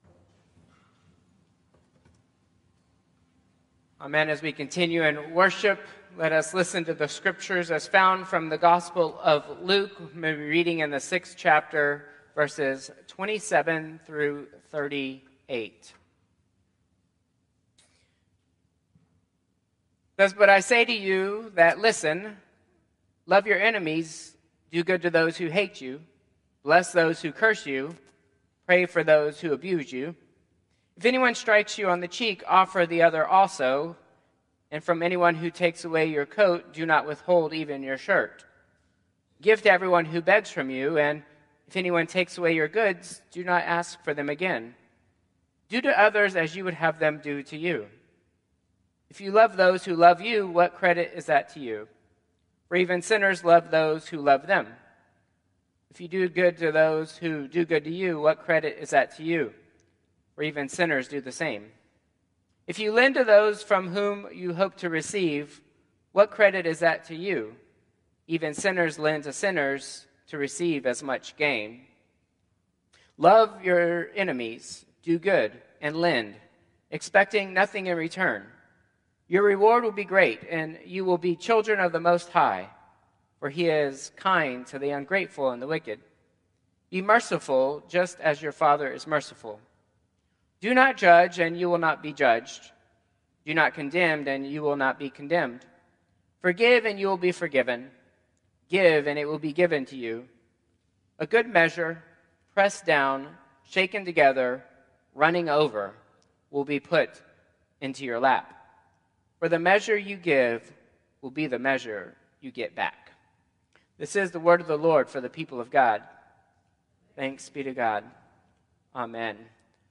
Traditional Service 2/23/2025